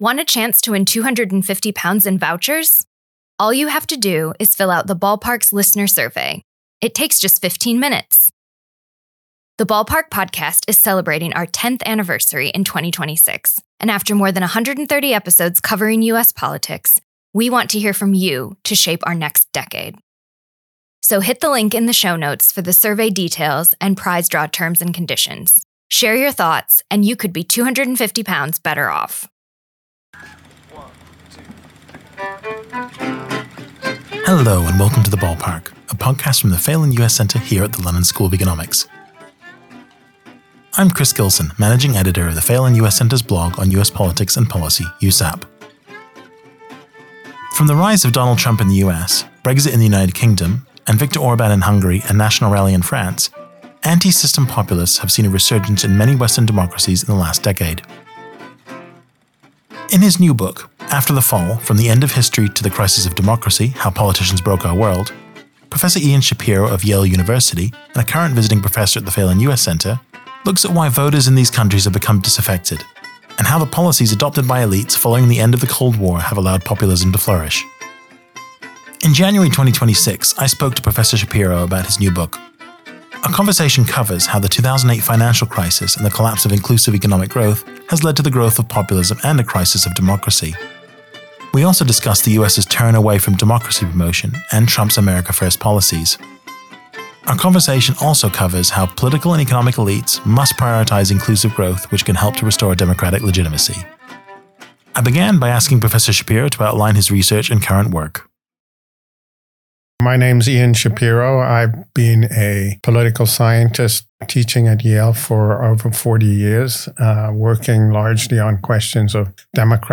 In January 2026 the Phelan US Centre spoke to Professor Shapiro about his new book. The conversation covered how the 2008 financial crisis and the collapse of inclusive economic growth has led to the growth of populism and a crisis of democracy.